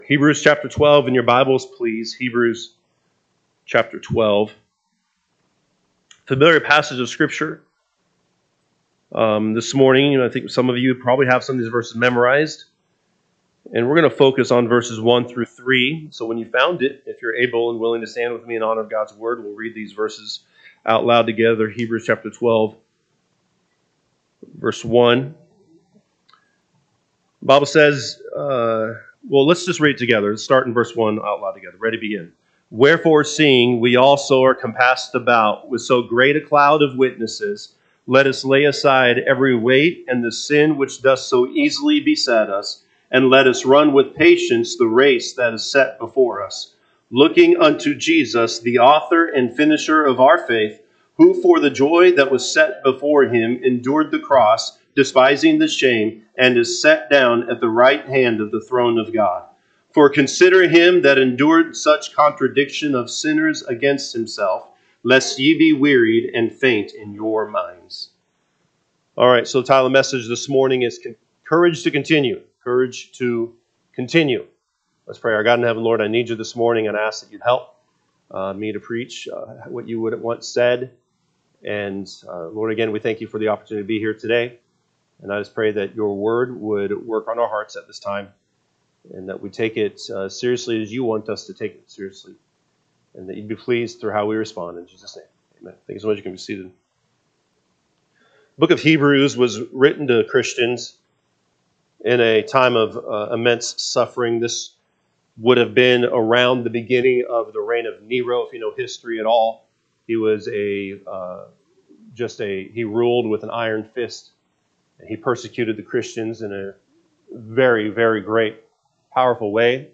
Sunday AM Message